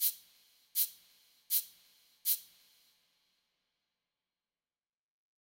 bass_chardi_arpeggio.wav